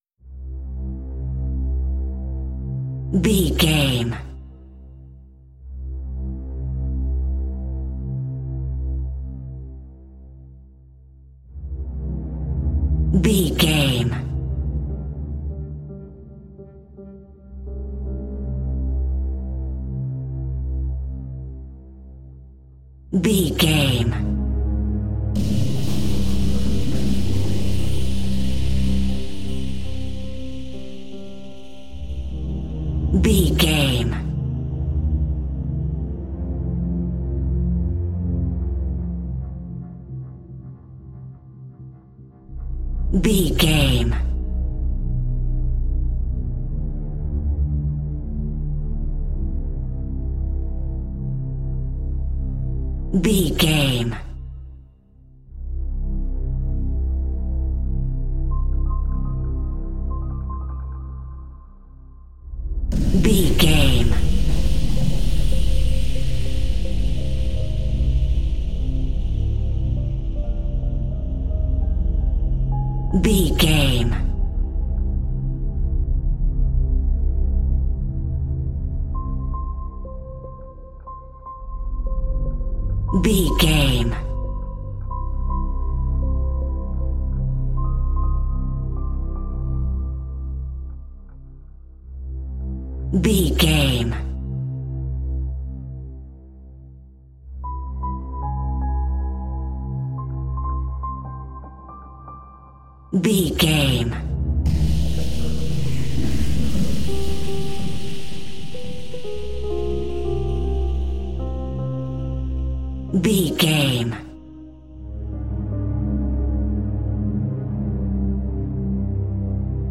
Aeolian/Minor
tension
ominous
eerie
synthesizer
piano
mysterious
Horror Pads
horror piano